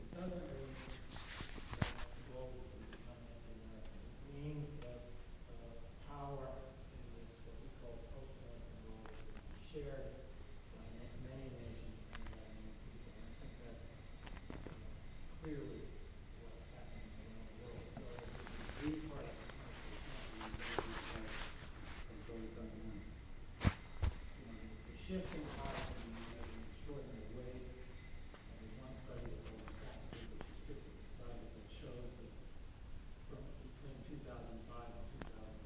A lecture
65582-a-lecture.mp3